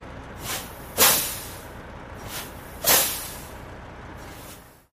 tr_dieseltruck_brake_01_hpx
Diesel truck air brakes release and screech. Vehicles, Truck Brake, Release